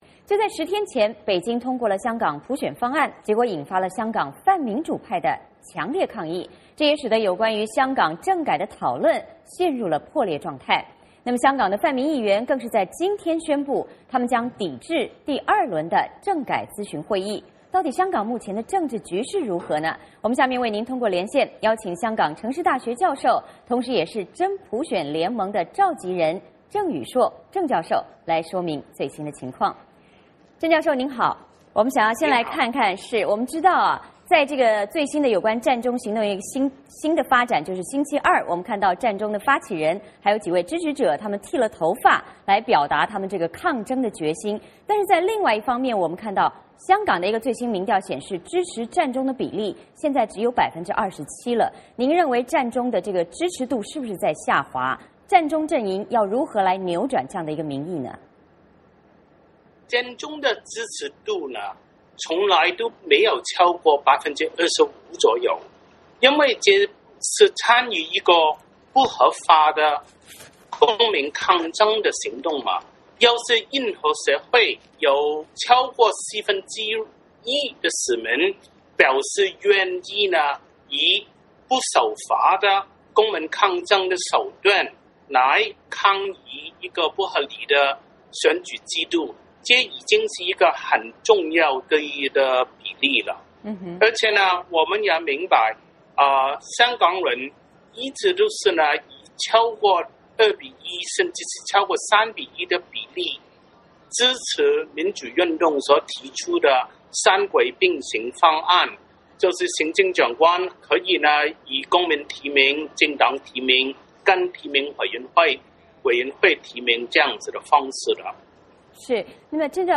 香港泛民议员今天宣布将抵制第二轮的政改咨询会议，香港目前的政治局势如何？我们通过连线